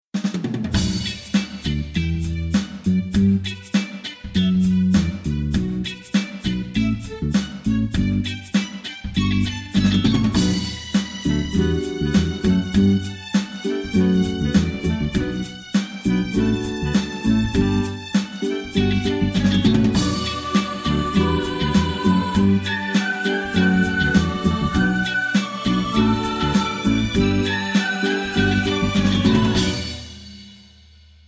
Pop Example